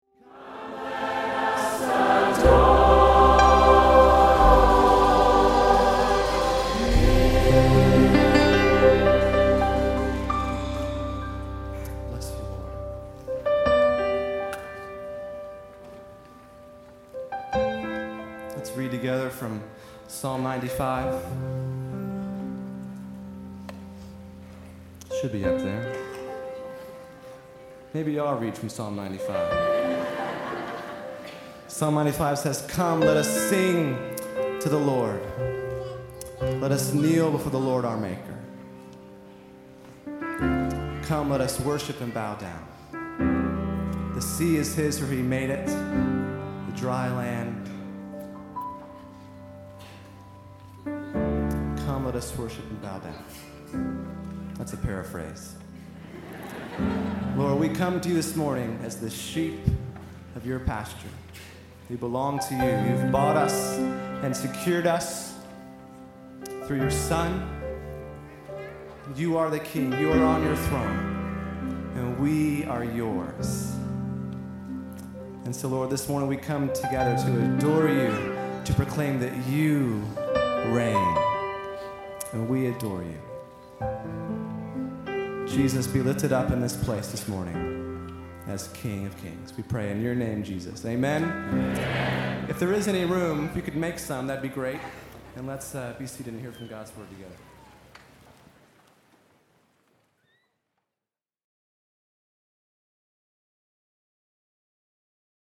Sunday May 13th was the day, and at the end of the opening time of worship at our 11:00am service, after we sang “Behold Our God”, I had planned for our congregation say Psalm 95:1-7.
People laughed.
Then, after fumbling my way through trying to remember how Psalm 95:1-7 went (and not doing a very good job), I said “(pause) that’s a paraphrase“.
me-messing-up-psalm-95.mp3